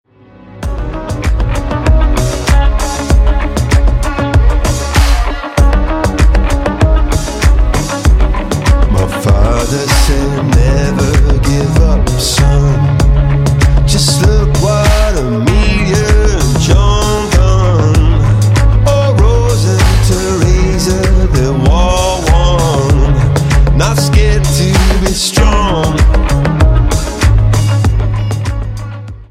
• Качество: 128, Stereo
мужской вокал
Хип-хоп
house